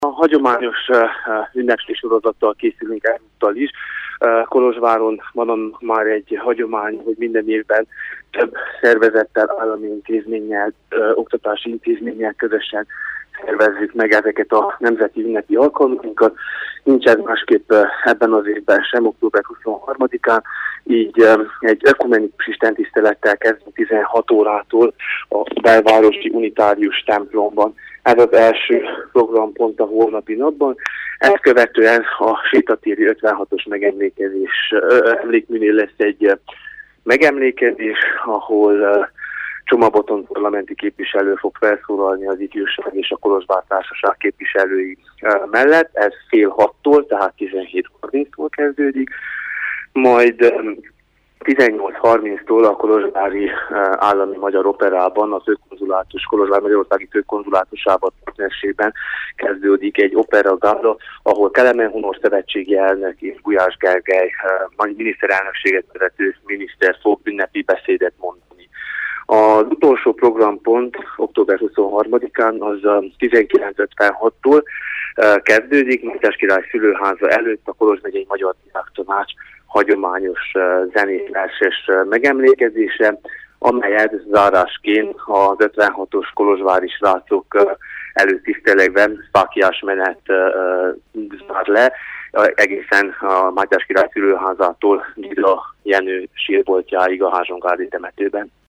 A programról Antal Géza megyei tanácsos beszélt a Kolozsvári Rádióban.